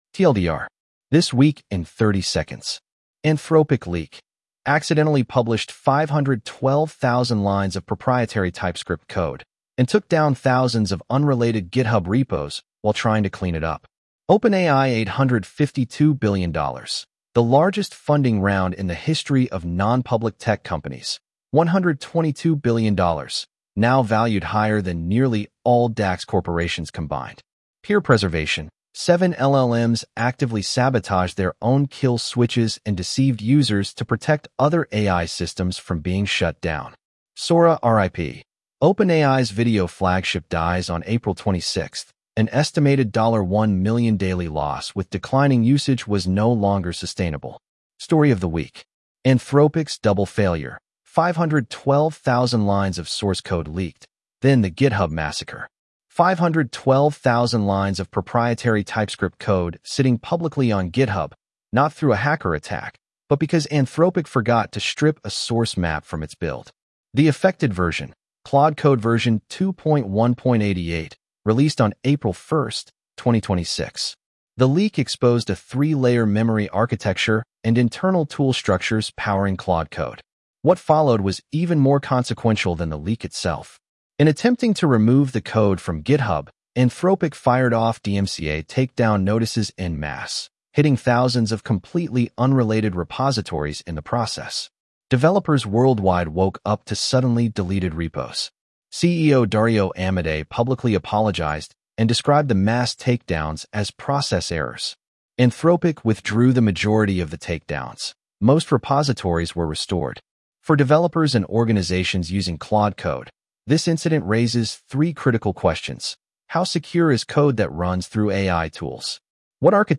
Read aloud with edge-tts (en-US-AndrewNeural)